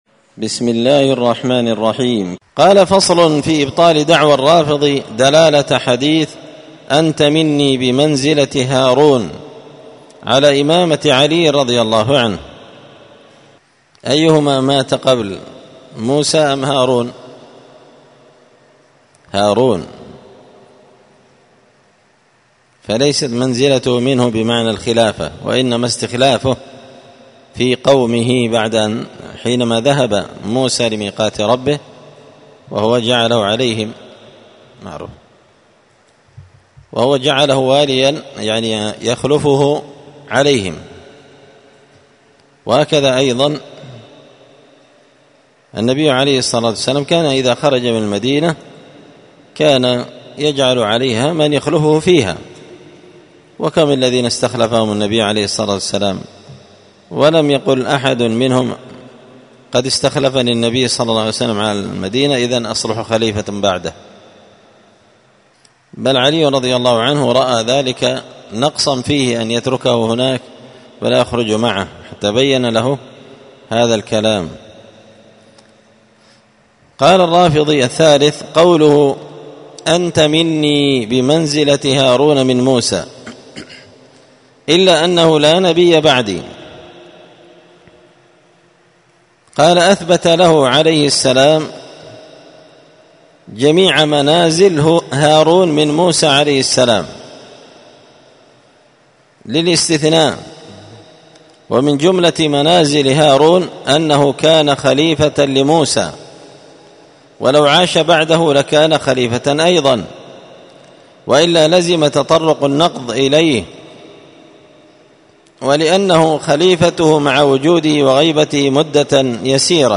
الأربعاء 21 صفر 1445 هــــ | الدروس، دروس الردود، مختصر منهاج السنة النبوية لشيخ الإسلام ابن تيمية | شارك بتعليقك | 84 المشاهدات
مسجد الفرقان قشن_المهرة_اليمن